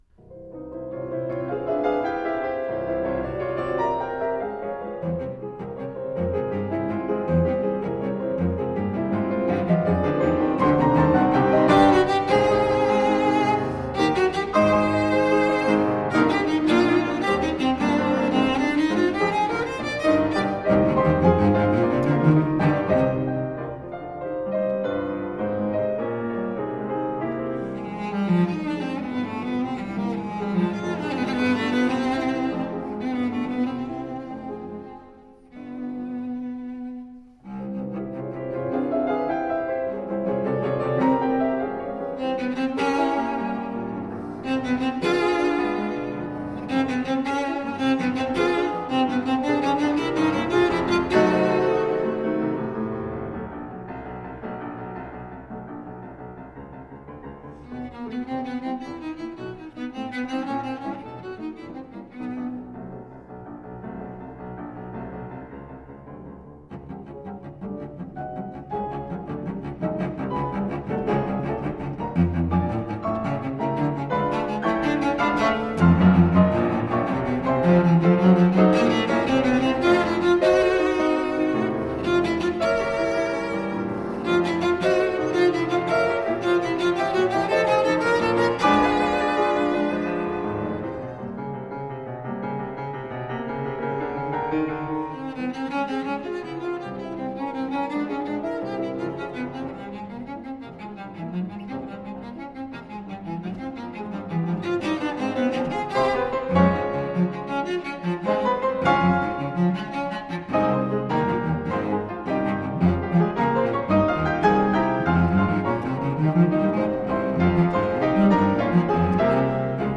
Cello sonata